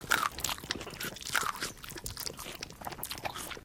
pdog_chew_1.ogg